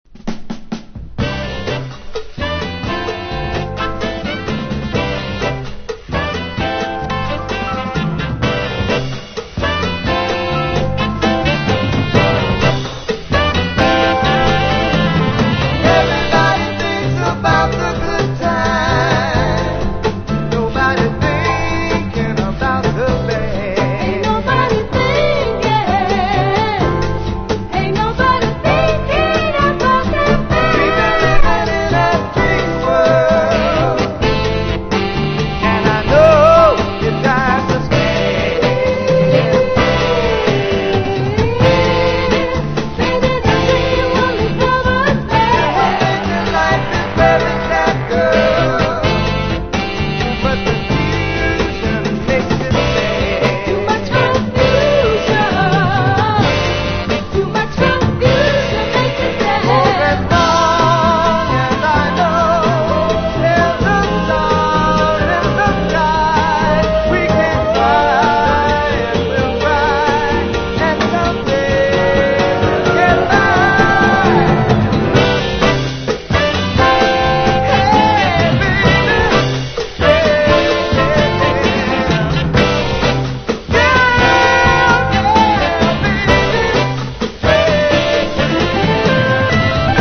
• 盤面 : EX+ (美品) キズやダメージが無く音質も良好